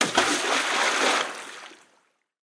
Song: SFX 1166